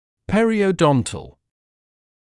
[ˌperɪəu’dɔntl][ˌпэриоу’донтл]пародонтологический, периодонтальный